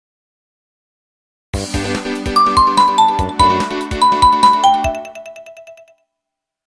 PowerOn (Alt).ogg